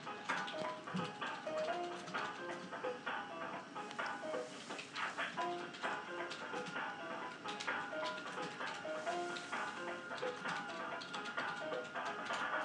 hold music